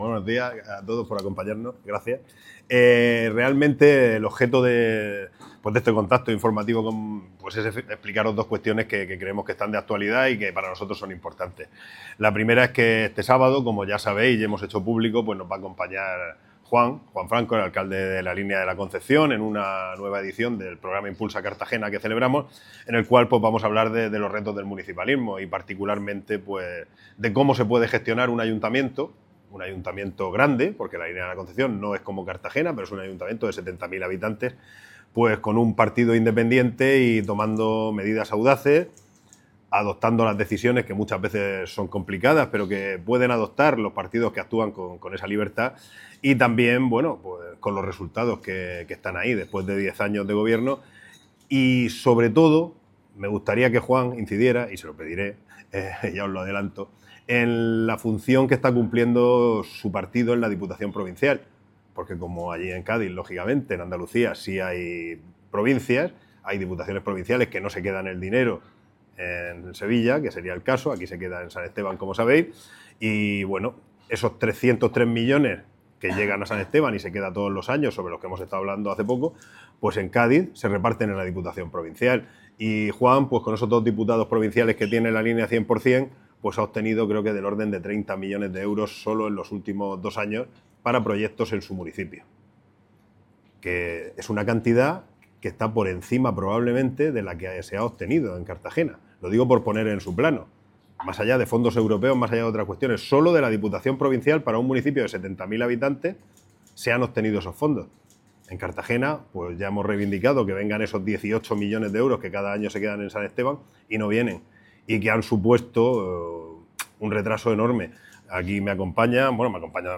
Audio: Declaraciones de Jes�s Gim�nez, MC Cartagena (MP3 - 21,99 MB)